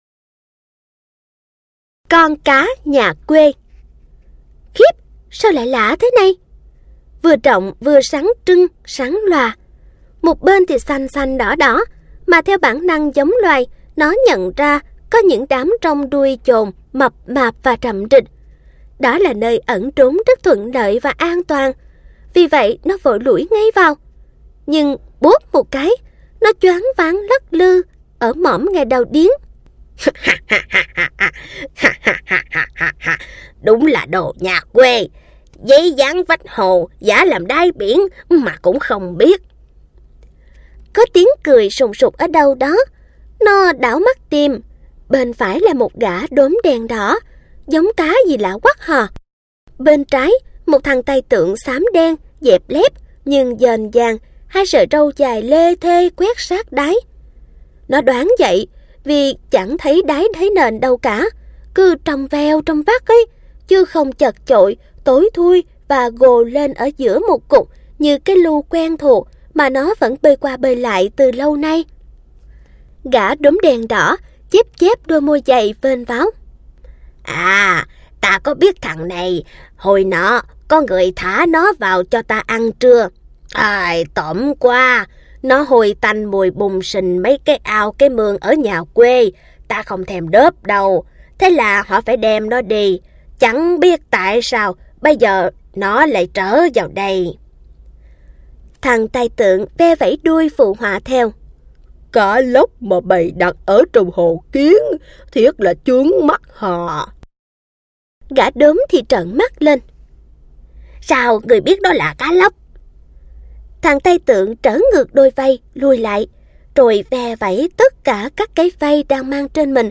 Sách nói | Con cá nhà quê